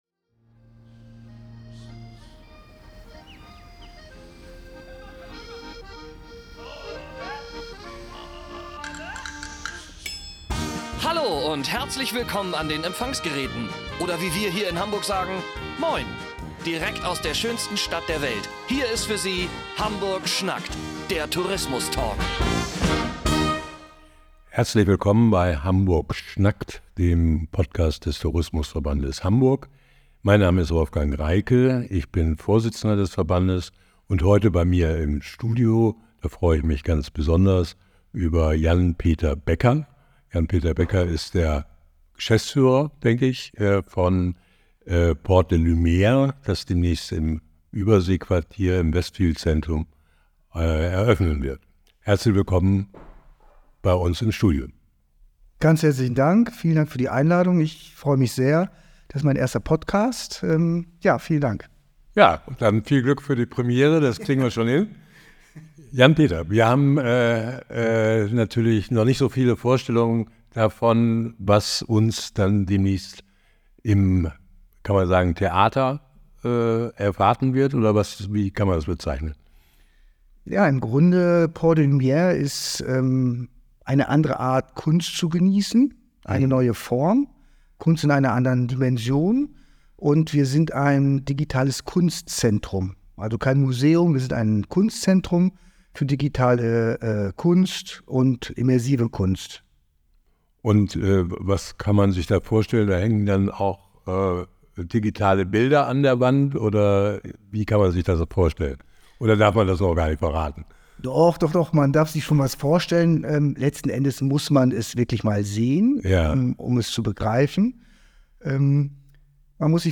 Hamburg schnackt – Der Tourismus Talk